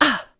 Theo-Vox_Damage_01.wav